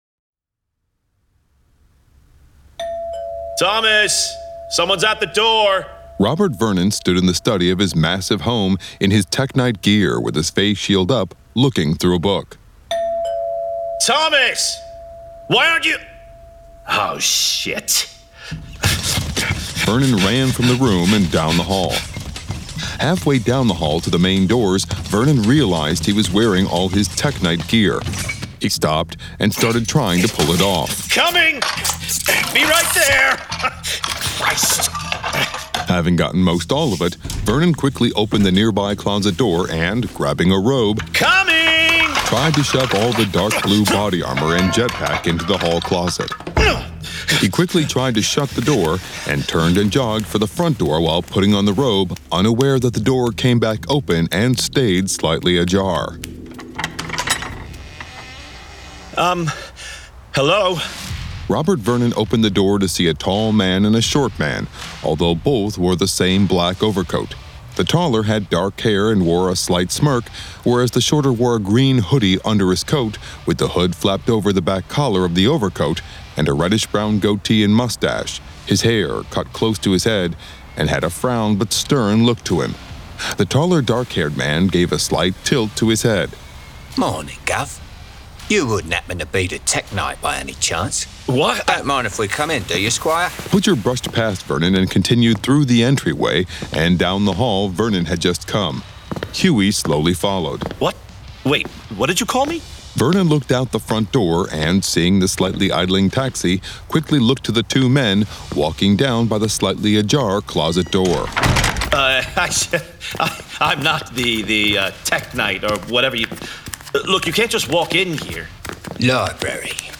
Full Cast. Cinematic Music. Sound Effects.
For the first time ever in audio! GraphicAudio and Dynamite Comics are proud to present THE BOYS audiobooks produced with a full cast of actors, immersive sound effects and cinematic music.